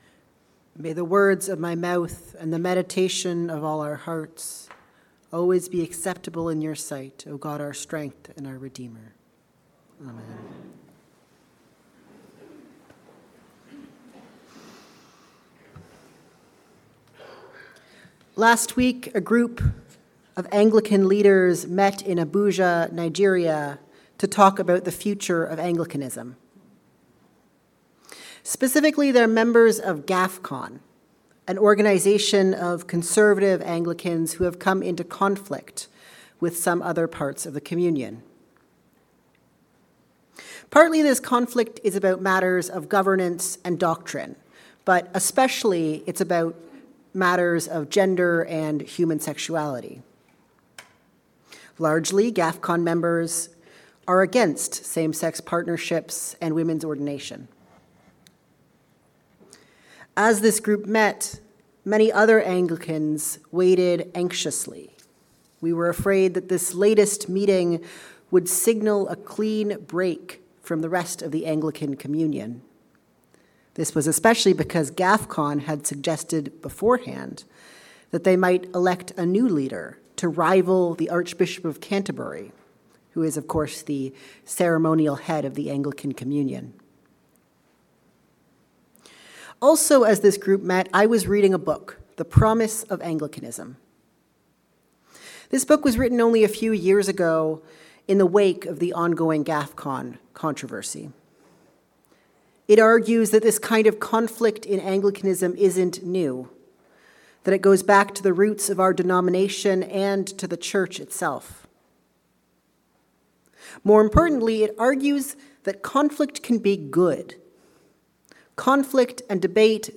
The Gift of Conflict. A sermon for the Fourth Sunday in Lent